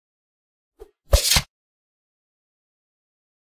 mandrake foundry13data/Data/modules/psfx/library/weapon-attacks/spear/v1